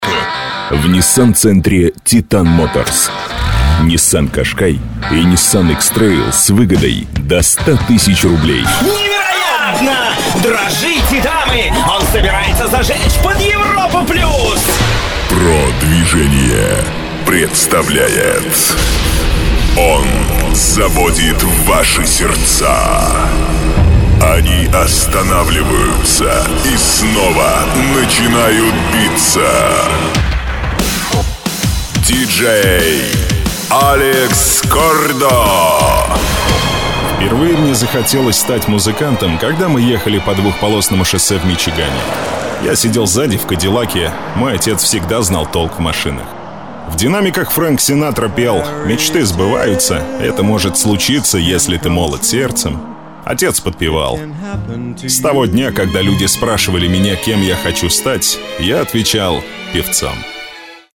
Тракт: Zoom H4n, шумоизолированное помещение.
Демо-запись №2 Скачать